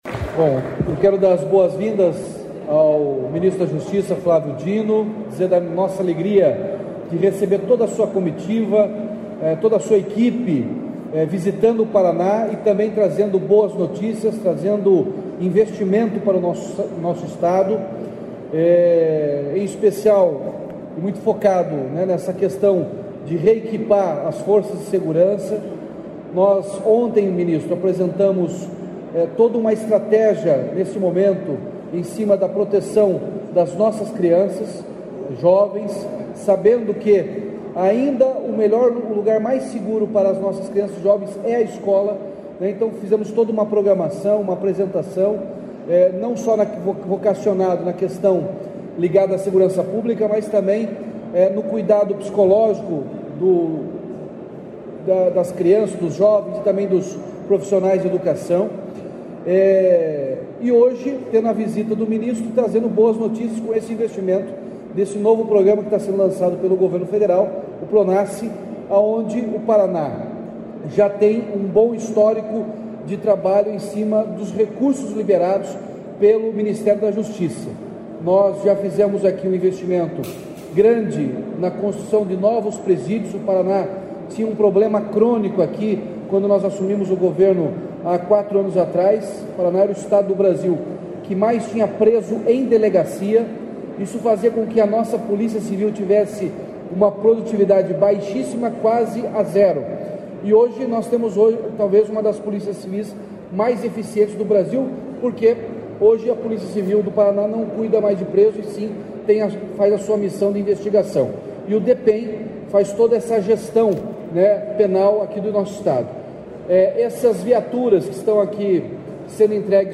Sonora do governador Ratinho Junior sobre o recebimento de viaturas do Ministério da Justiça para coibir violência contra a mulher